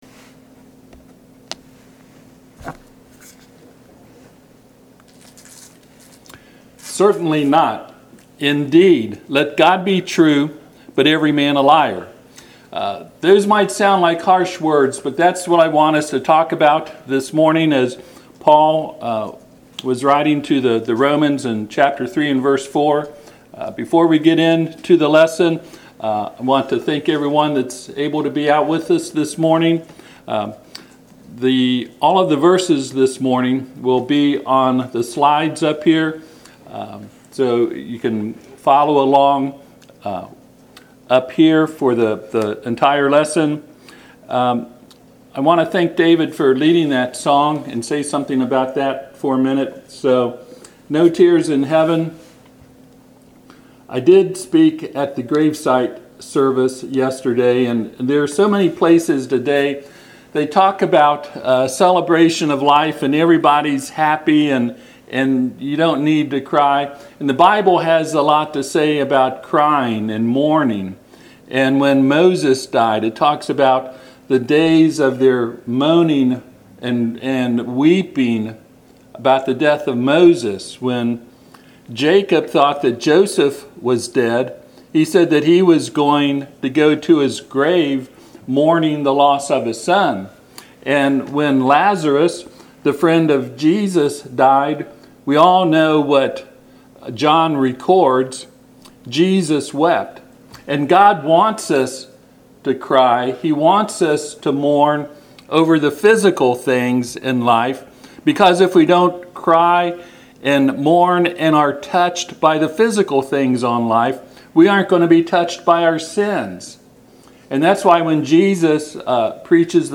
Romans 3:4 Service Type: Sunday AM https